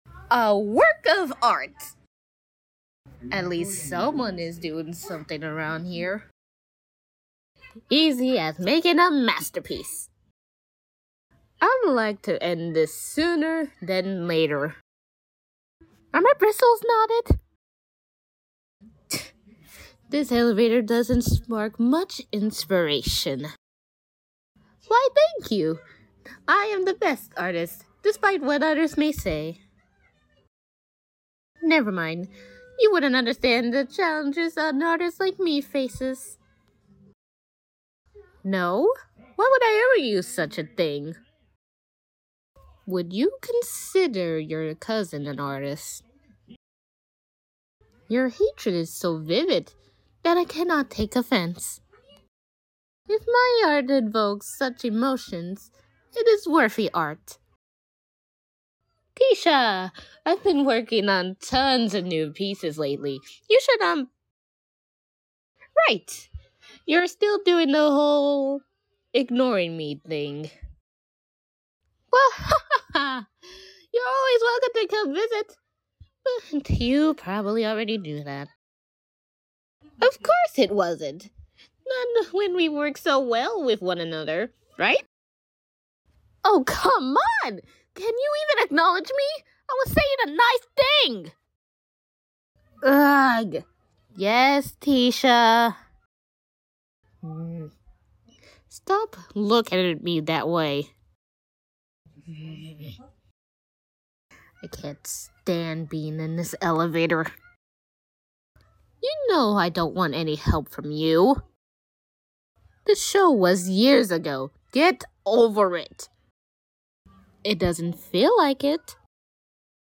[VOICING OVER BRUSHA’S (current) LINES] Sound Effects Free Download
[VOICING OVER BRUSHA’S (current) LINES] Yes I used my voice for this . . . .